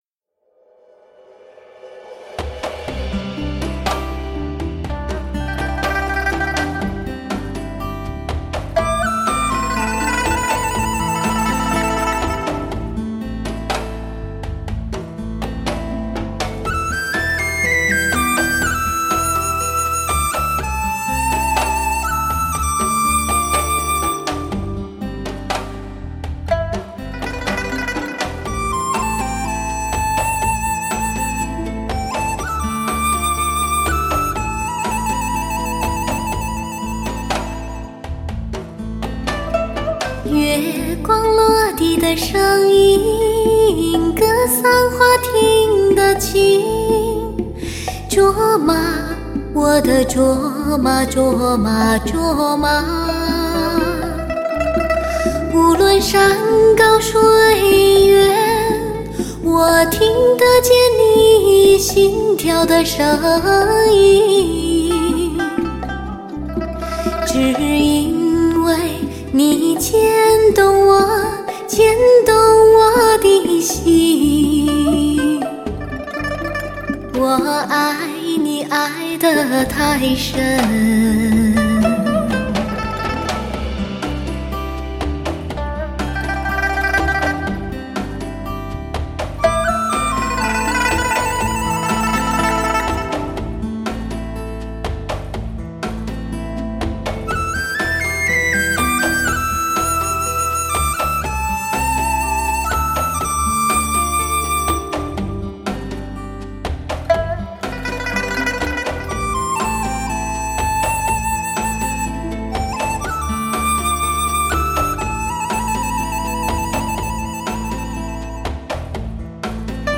灵动的音符，蓝天下最动人的歌喉，感动你我的雪山情怀；
磁性通彻，细密清新的发烧声线，极致天籁女声；
全新演绎，深厚的唱功和对歌曲的通彻感悟，爆发出最动人的真情。